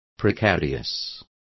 Complete with pronunciation of the translation of precarious.